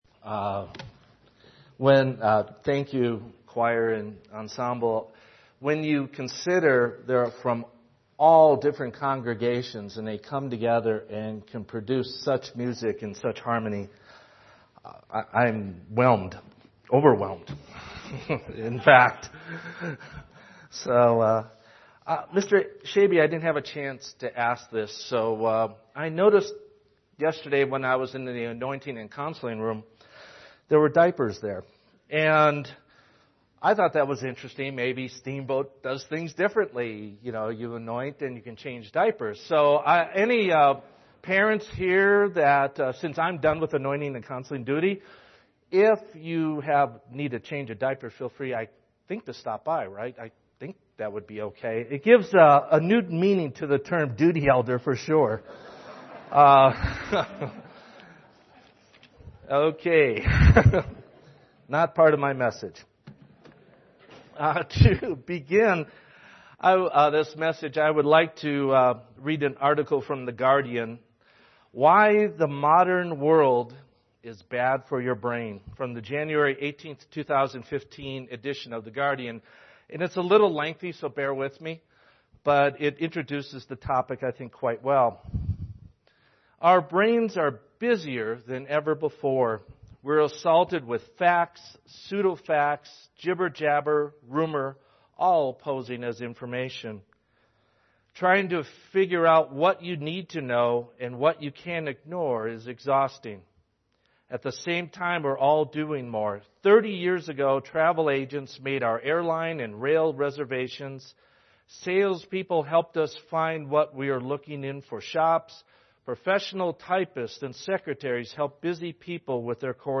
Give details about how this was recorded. This sermon was given at the Steamboat Springs, Colorado 2015 Feast site.